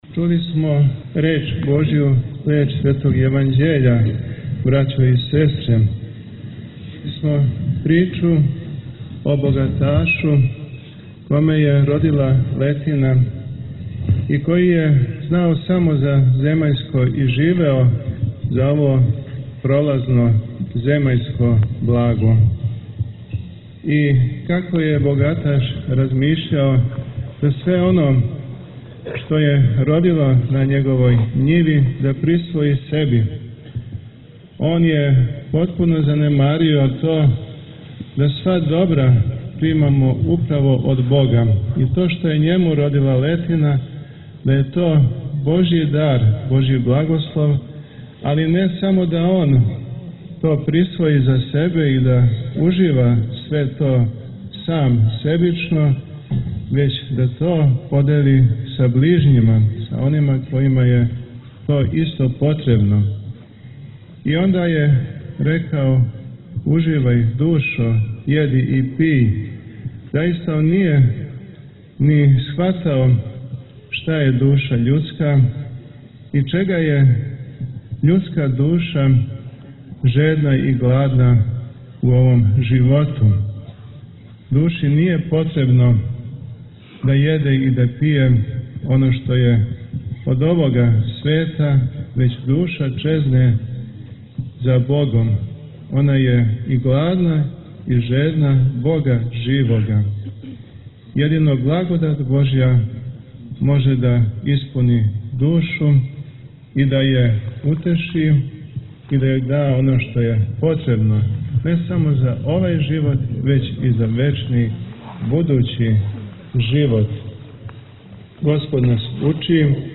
Епископ рашко-призренски Теодосије служио је 15. децембра, на празник Преподобног Јоаникија Девичког, Свету Литругију у Манастиру Девич, у присуству више од хиљаду верника.